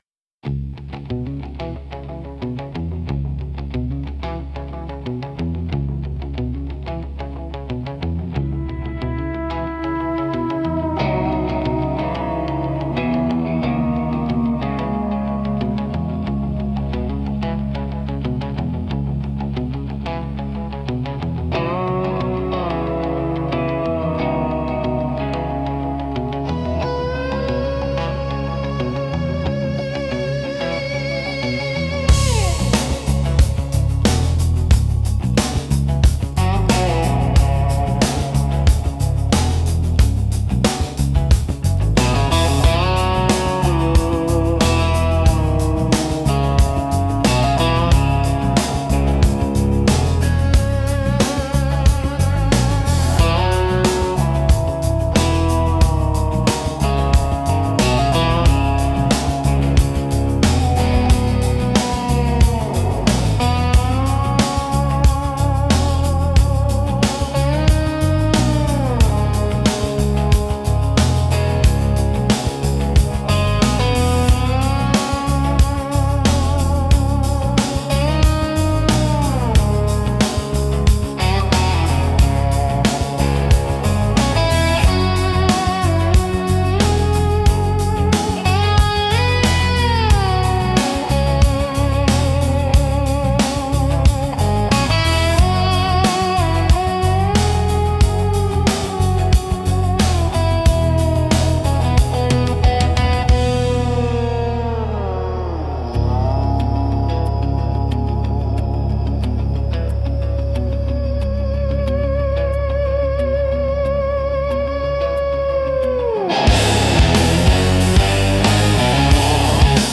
Instrumental - Steel-String Soliloquy 3.44